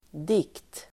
Uttal: [dik:t]